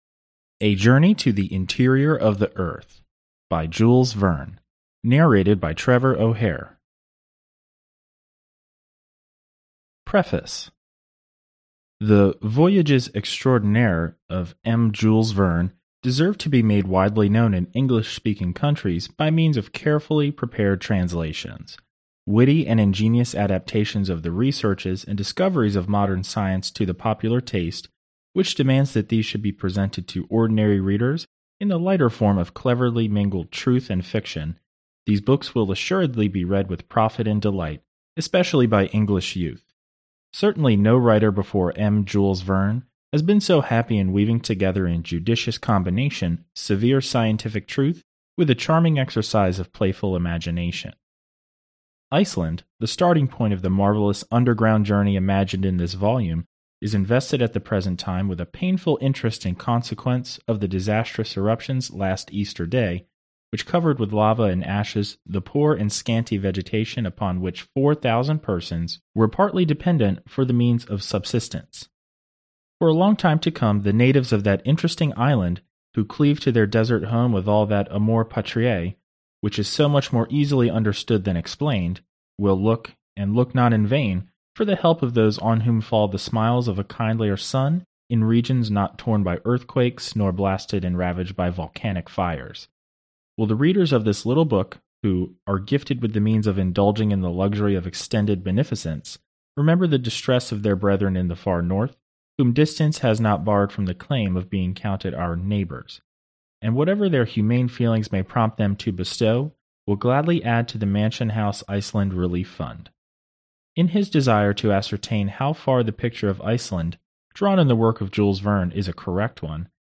Аудиокнига A Journey to the Interior of the Earth | Библиотека аудиокниг